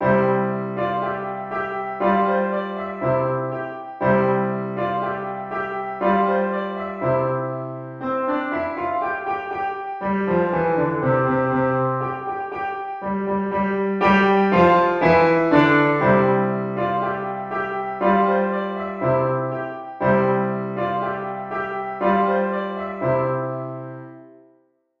Alouette for Easy/Level 2 Piano Duet
alouette-piano-duet.mp3